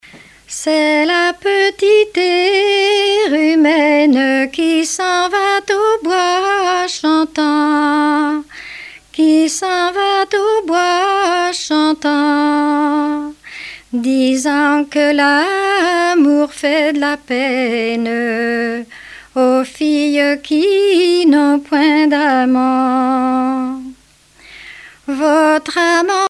Genre strophique
Chansons et contes traditionnels
Pièce musicale éditée